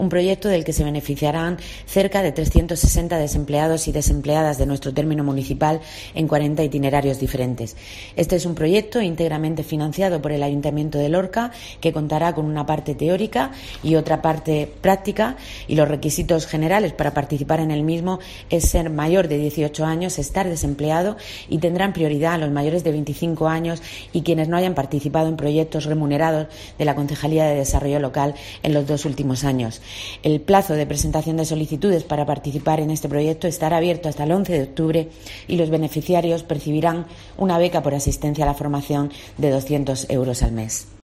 Isabel Casalduero, edil del Ayuntamiento de Lorca